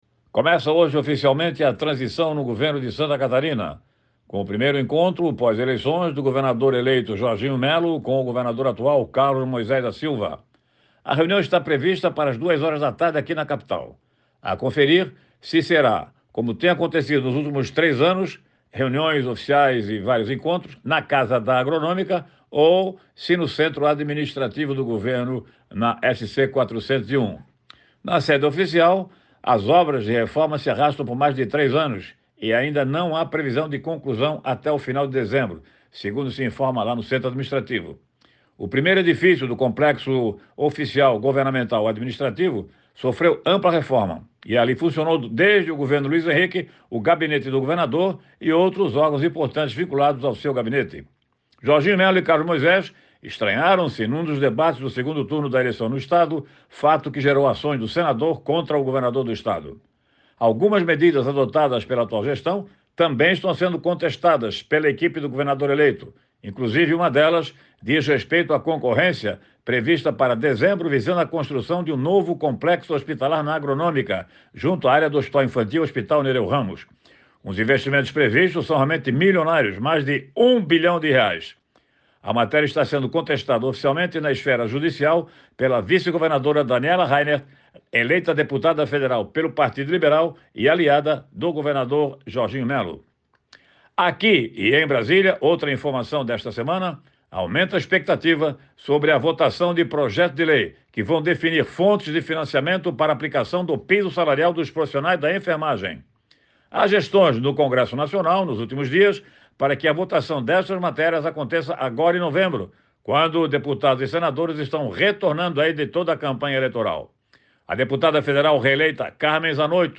Jornalista analisa o início da transição do governo de Santa Catarina e a votação do Projeto de Lei que define as fontes de financiamento para o piso da enfermagem